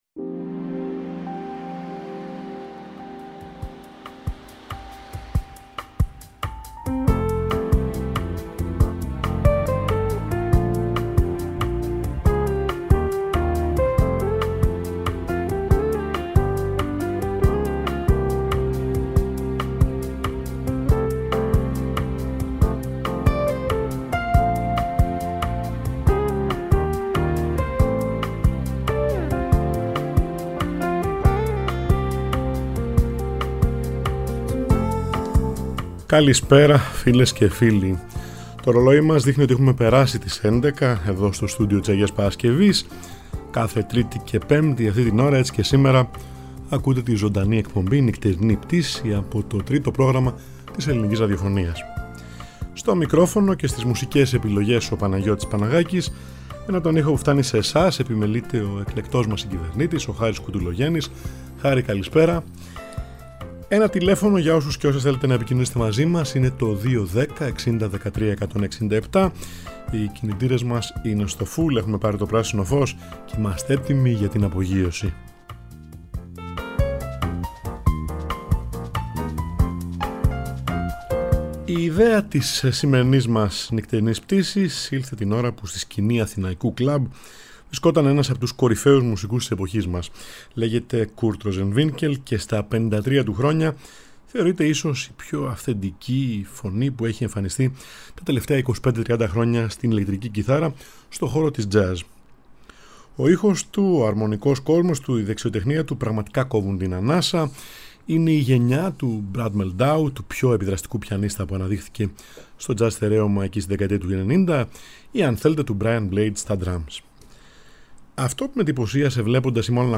Σχεδιάζοντας περίτεχνα ηχητικά τοπία.